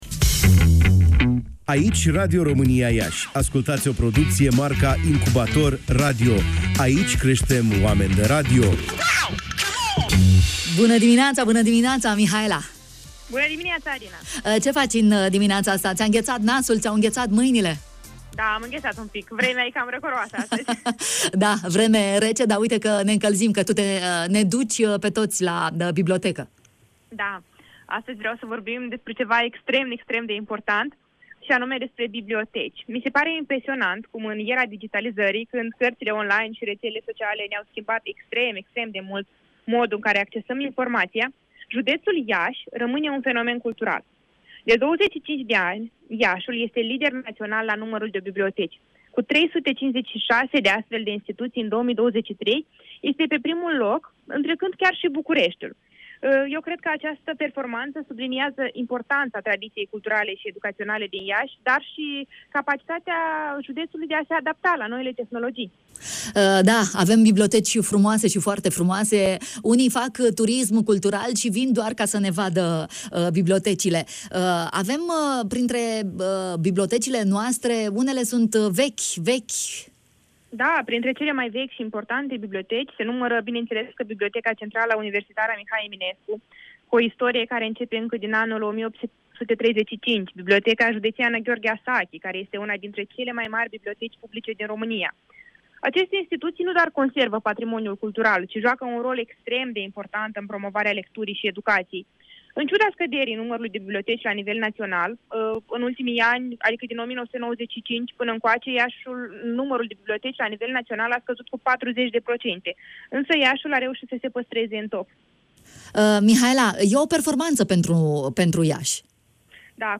Care este una dintre cele mai vechi biblioteci din Iași, am aflat în matinalul Radio România Iași